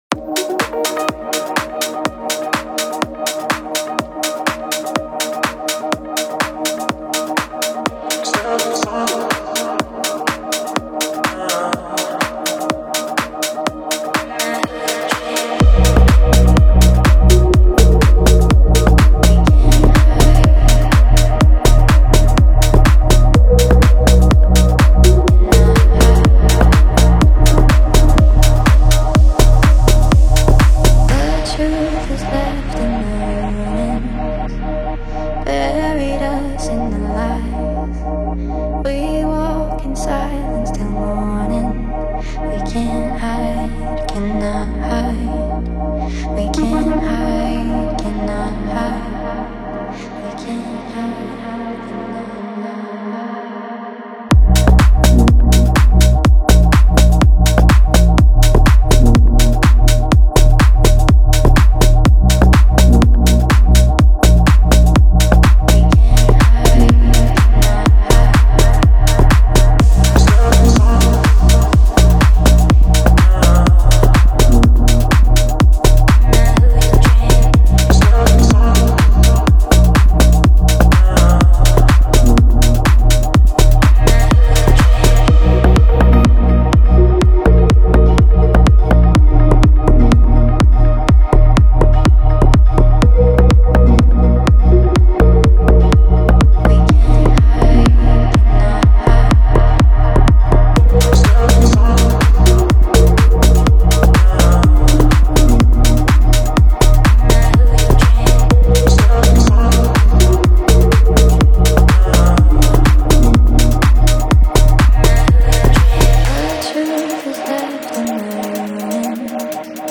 • Жанр: Dance, House